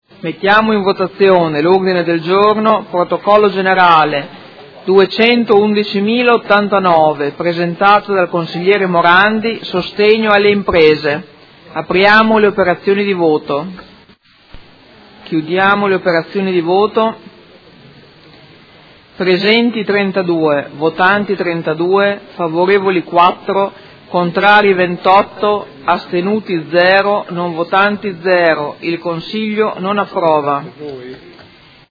Seduta del 20/12/2018. Mette ai voti Ordine del Giorno Prot. Gen. 211089